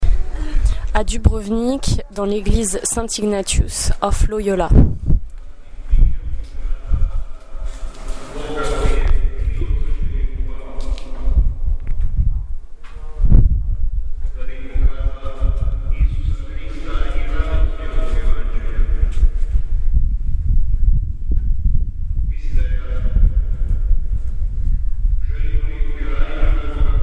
à l'intérieur de l'église de Saint Ignatius de Loyola.